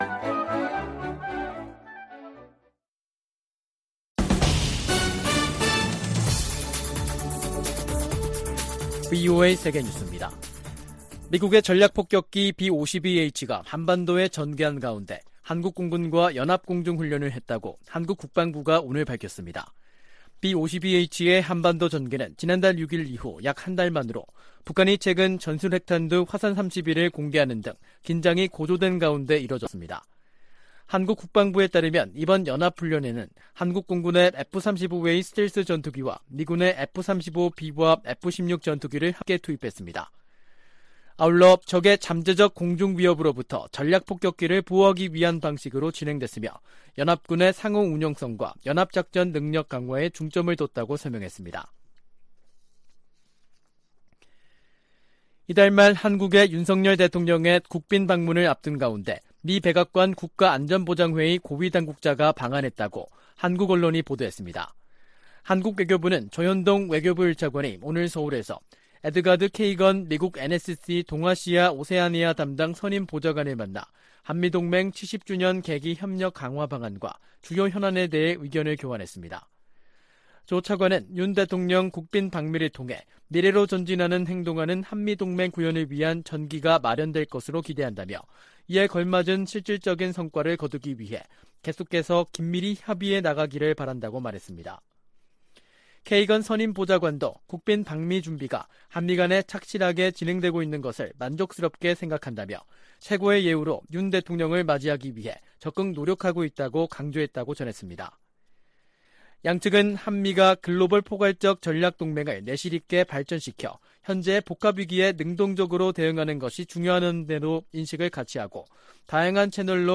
VOA 한국어 간판 뉴스 프로그램 '뉴스 투데이', 2023년 4월 5일 3부 방송입니다. 유엔 인권이사회가 북한의 조직적인 인권 침해를 규탄하고 개선을 촉구하는 내용의 북한인권결의안을 채택했습니다. 미국 국무장관이 현재 당면한 도전은 세계적인 것이라며 나토와 아시아 국가들의 협력 확대 중요성을 강조했습니다. 미국 공군이 시험 발사할 대륙간탄도미사일(ICBM) 미니트맨 3는 북한 정권을 끝낼 수 있는 위력을 가진 무기라고 미국 군사 전문가들이 평가했습니다.